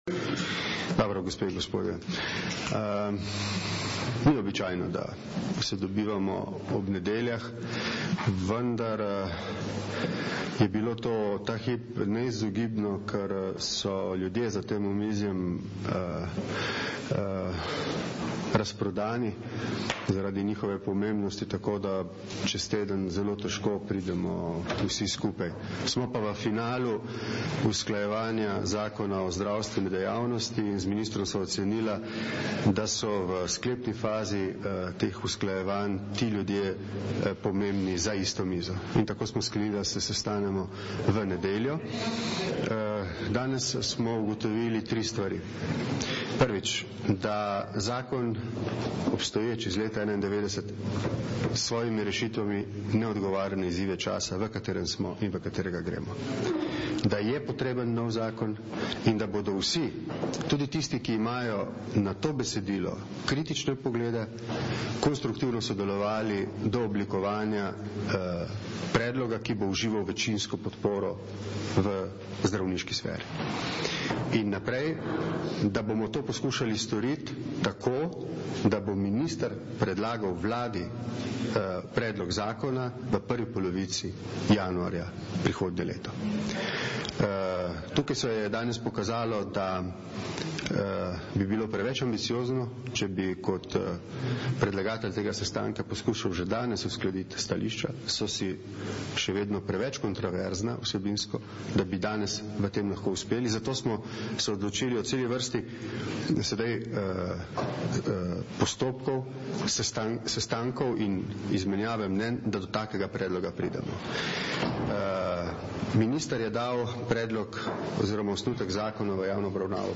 Zvočni zapis izjave predsednika Vlade RS